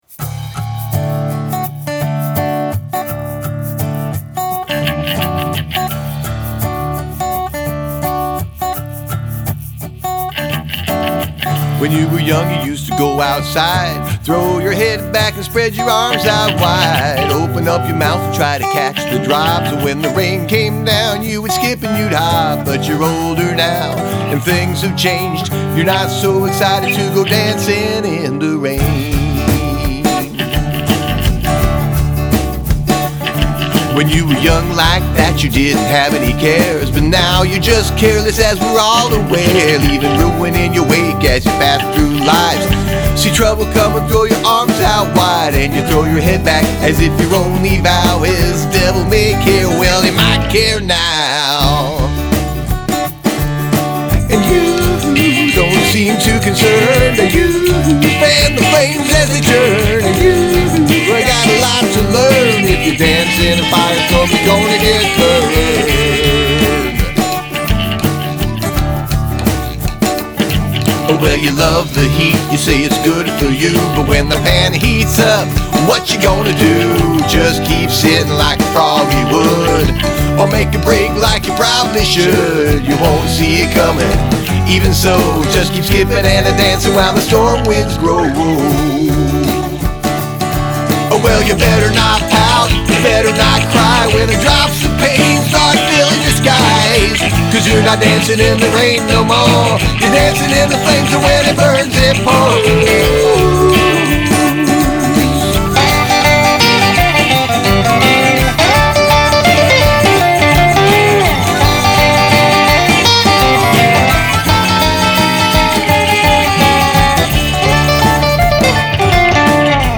Use of Falsetto